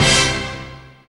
SI2 STACCATO.wav